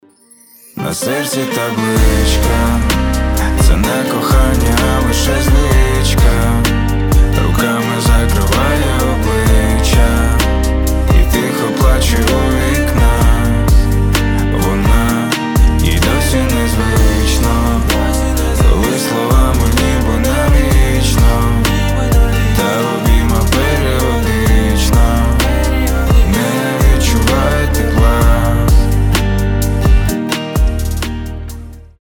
лирика
спокойные
медленные